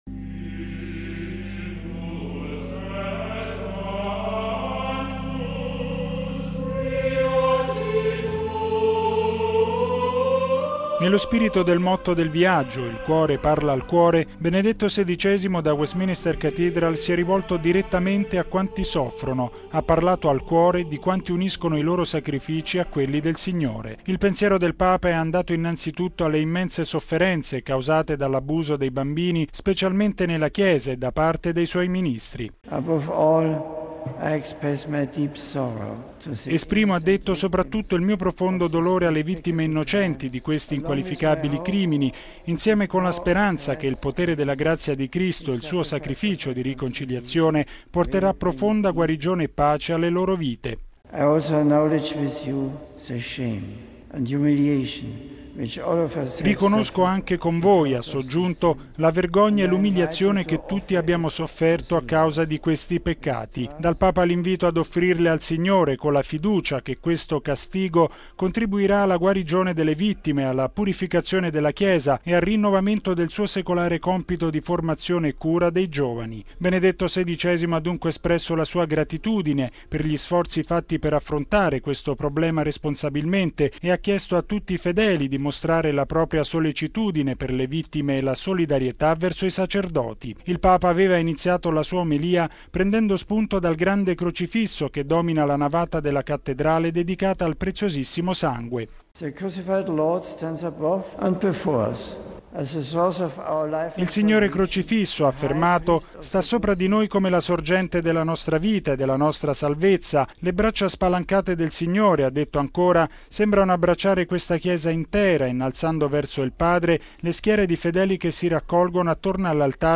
◊   Dolore, umiliazione, rinnovamento: sono i sentimenti espressi oggi dal Papa, durante la Messa a Westminster Cathedral, sulla dolorosa vicenda degli abusi sui minori nella Chiesa.
(Canti)
(Cori – applausi dei giovani)